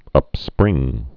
(ŭp-sprĭng)